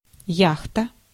Ääntäminen
IPA : /jɒt/